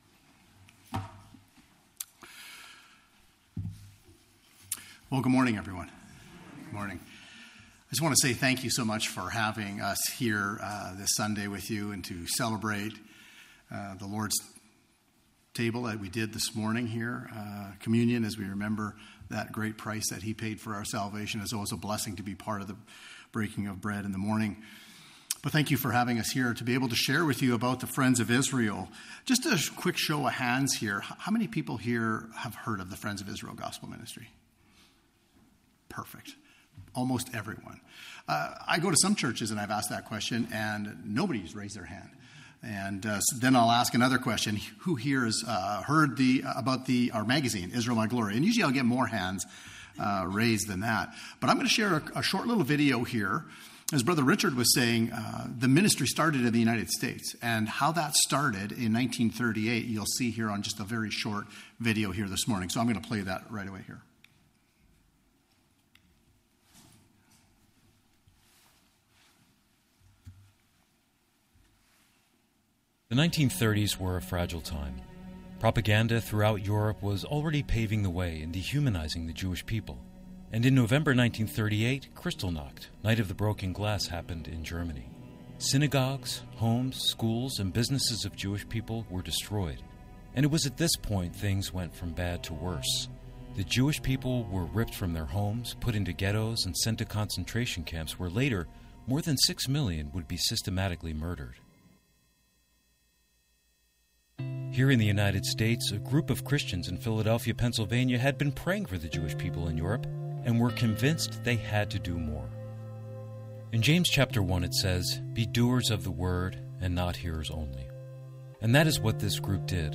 Service Type: Family Bible Hour